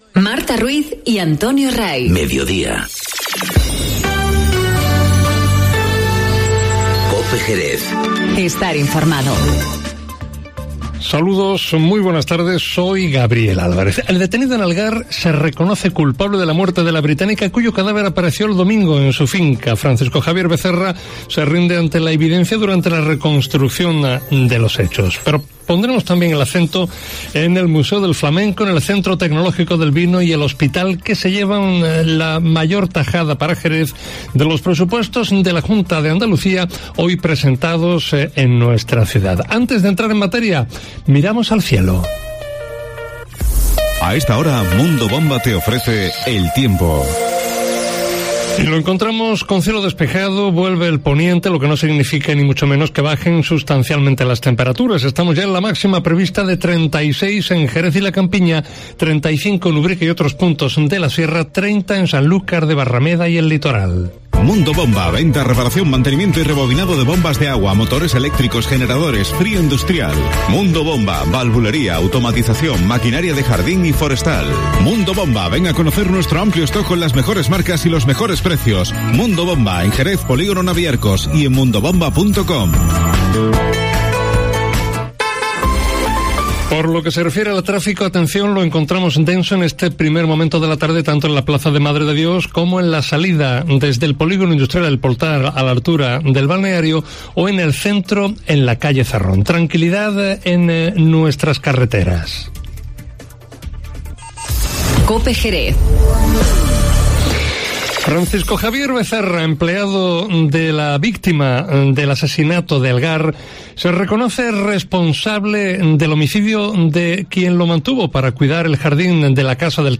Informativo Mediodía COPE en Jerez 24-07-19